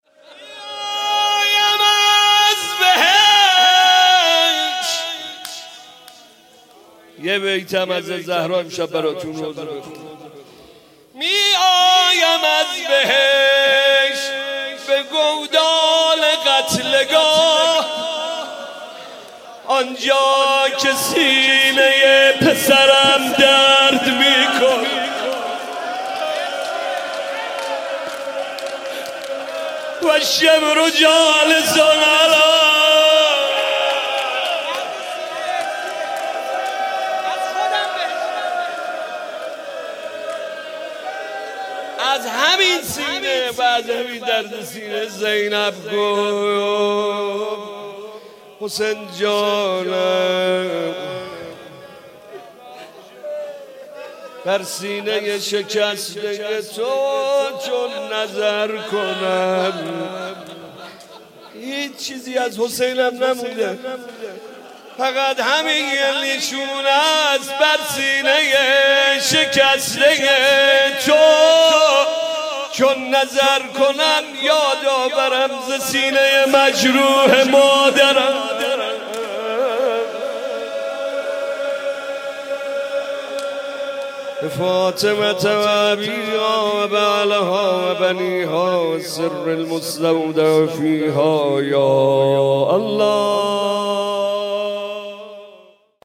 مداحی و نوحه
مناجات و روضه خوانی فاطمیه ۱۳۹۶